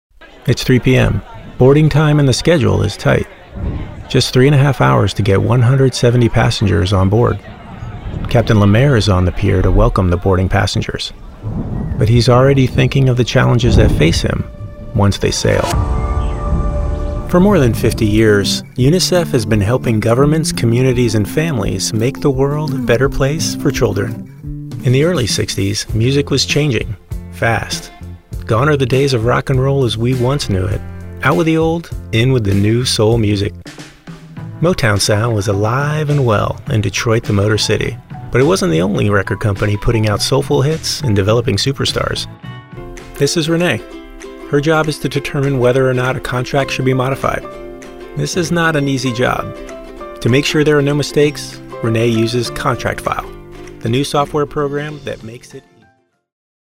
Male
English (North American)
Adult (30-50), Older Sound (50+)
My voice has been described as professional, calm, warm, friendly and soothing.
Narration
Documentary And Book Sample
All our voice actors have professional broadcast quality recording studios.